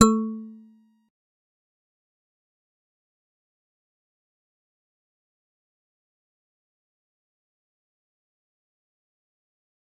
G_Musicbox-A3-pp.wav